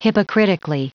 Prononciation du mot hypocritically en anglais (fichier audio)
Prononciation du mot : hypocritically